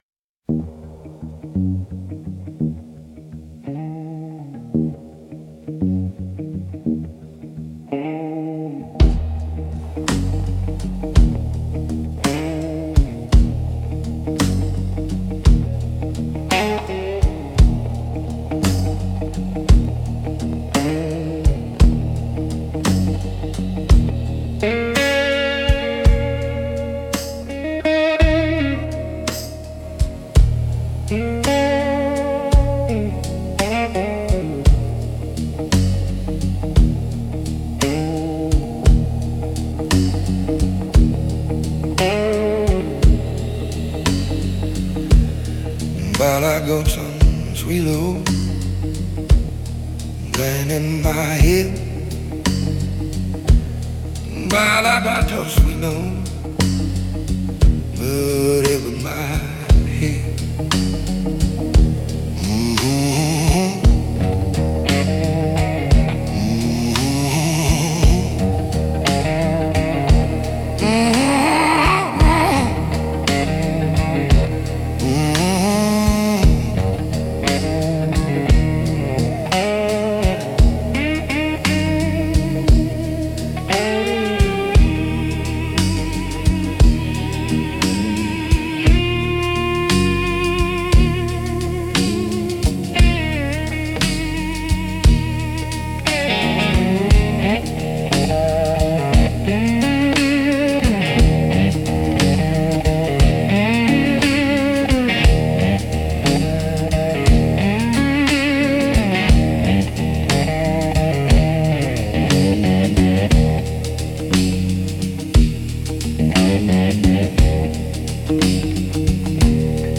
Instrumental - Bones of the Bayou